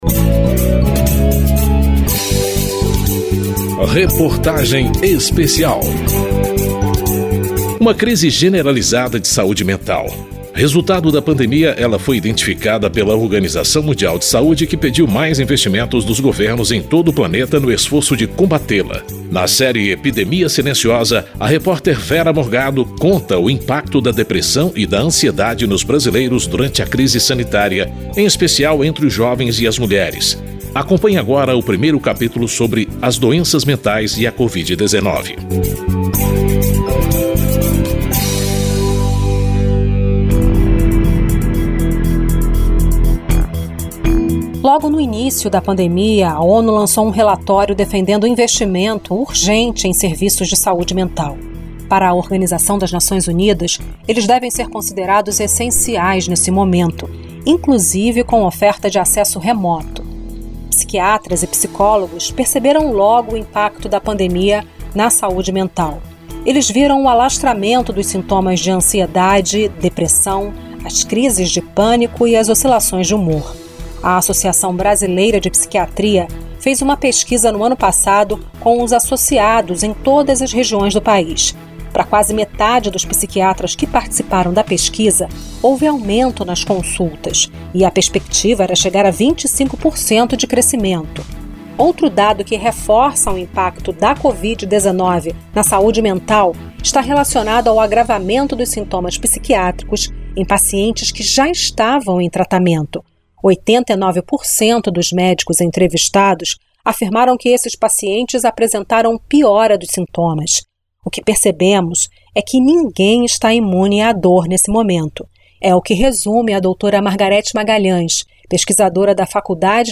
Reportagem Especial
E o deputado Lucas Gonzalez (Novo-MG), presidente da Frente Parlamentar de Combate ao Suicídio e à automutilação.